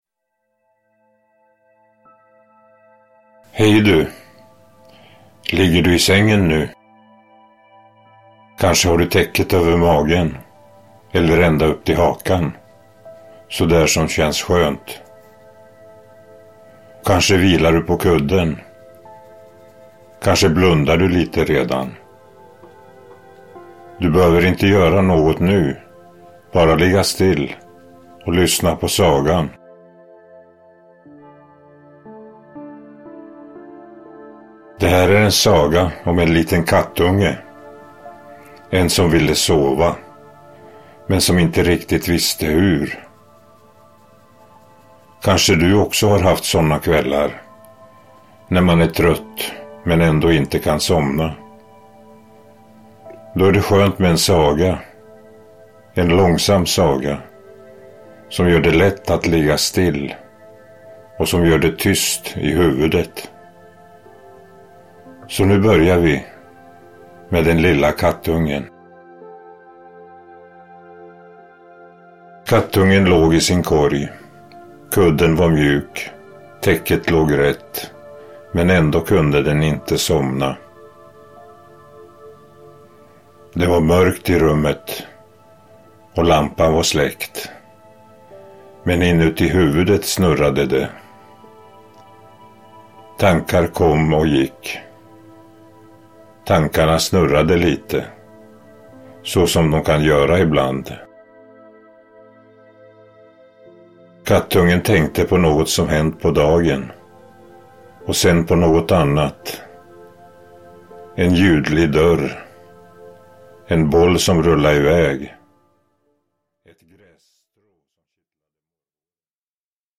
Ljudbok
Med långsamt tempo, stillsamt språk och återkommande rytmer hjälper berättelsen barnet att slappna av – steg för steg.
Tystnaden mellan meningarna är inte tom – den är en plats för vila.